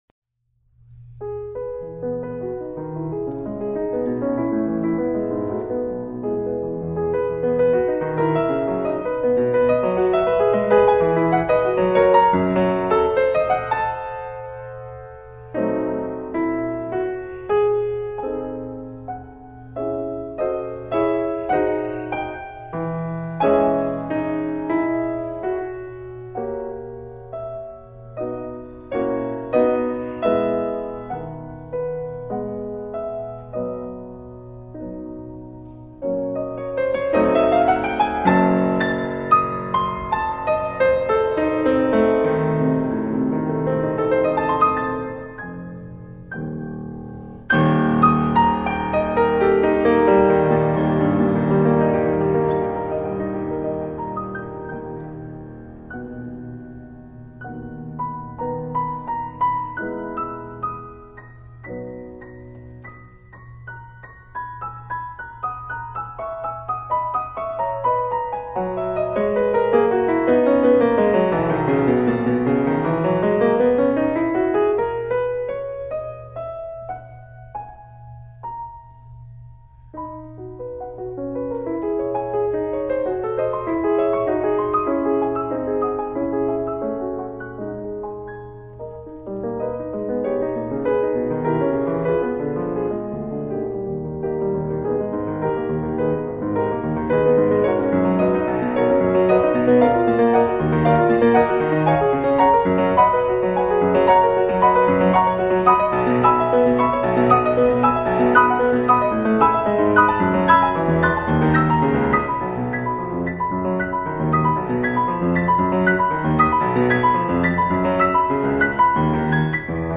Piano. 2 MB.
beeth_piano_sonata__op.mp3